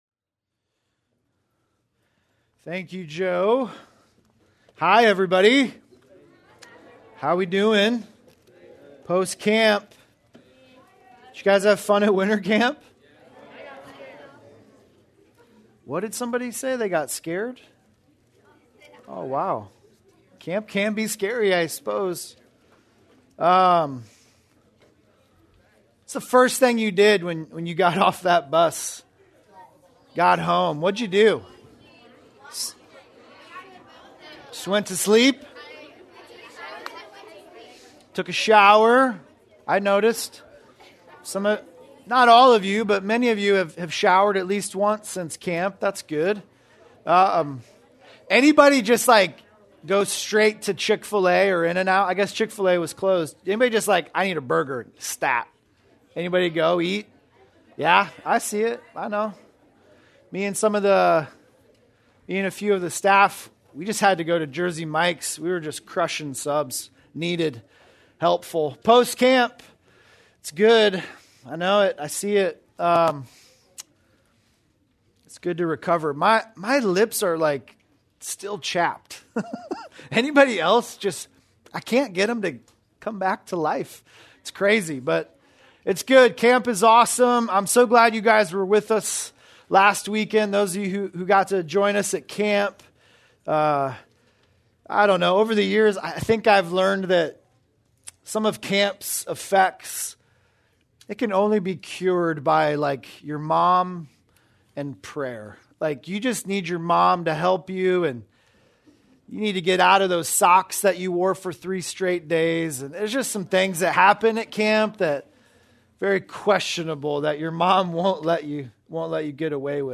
Post-Camp Sermon